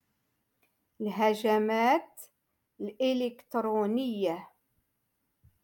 Moroccan Dialect-Rotation Five-Lesson Sixty Two